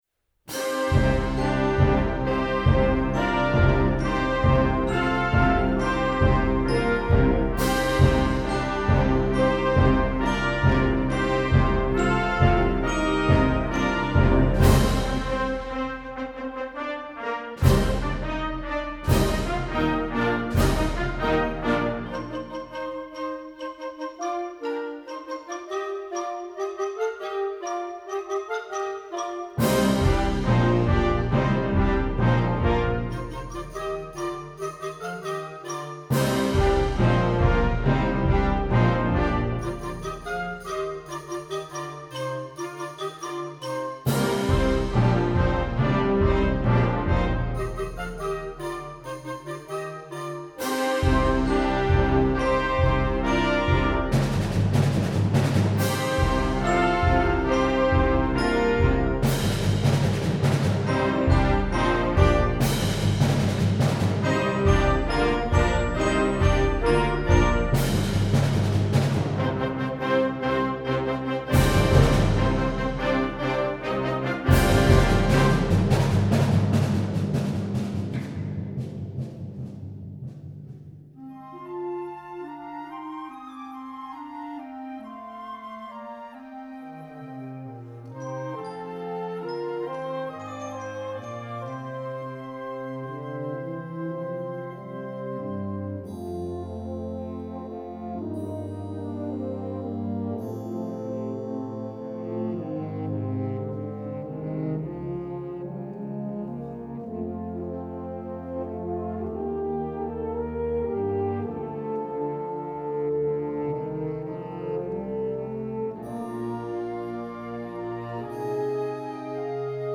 Genre: Band
7/8 time signatures
Flute 1/2
Oboe
Bassoon
Bass Clarinet
Alto Saxophone 1/2
Trumpet in Bb 1
Horn in F
Trombone
Euphonium
Tuba
Timpani
Percussion 1 (Bells, Xylophone)
Percussion 2/3 (Snare Drum, Bass Drum, Tambourine)
Percussion 4 (Suspended Cymbal, Crash Cymbals, Triangle)